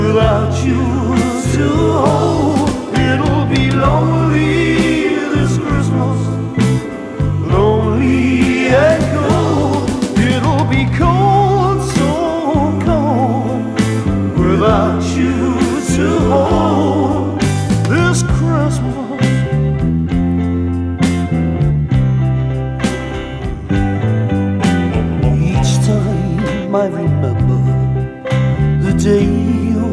• Pop
Christmas song
glam rock band